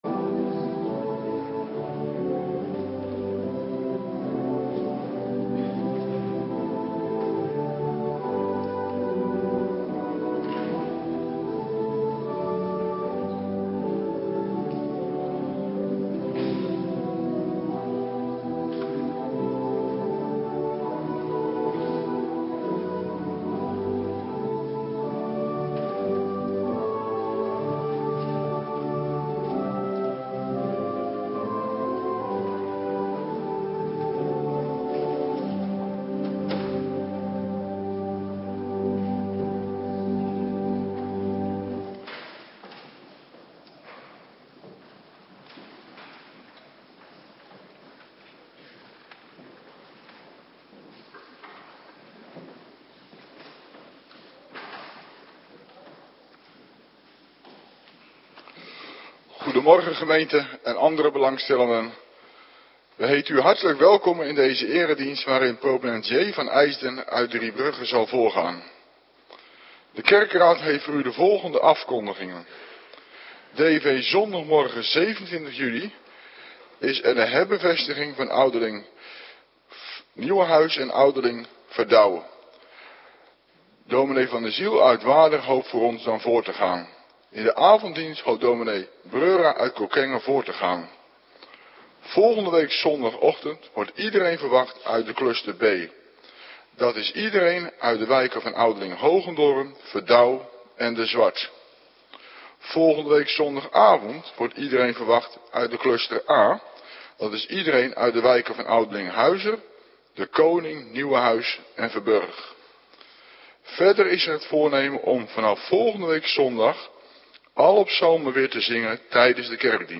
Morgendienst - Cluster A